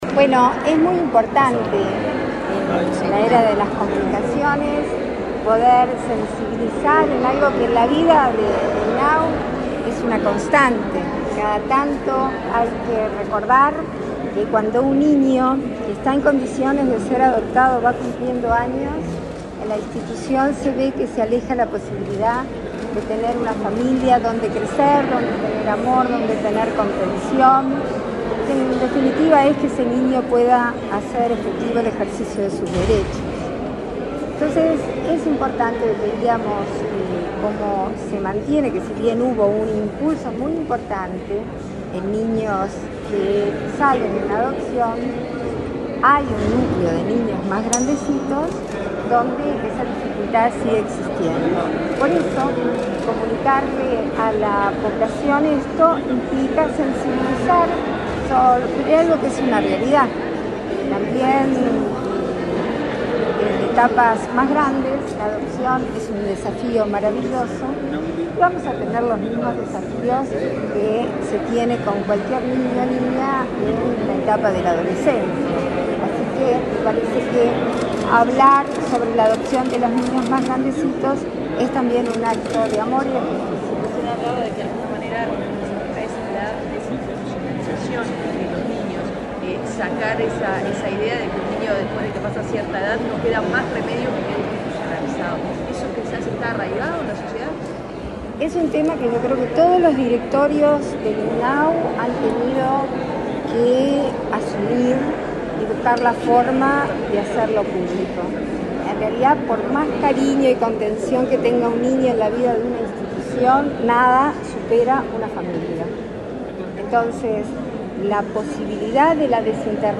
Declaraciones a la prensa de la vicepresidenta de la República, Beatriz Argimón